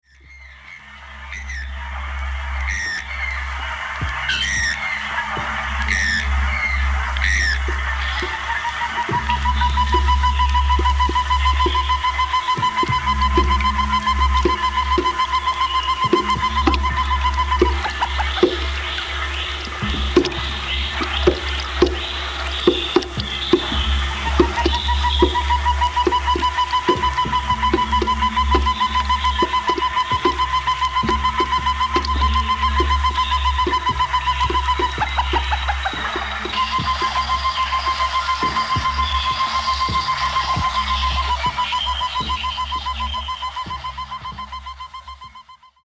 増幅された森の霊気と変調された動物の鳴き声が迫りくる激ドープな音響体験。
サウンドスケープ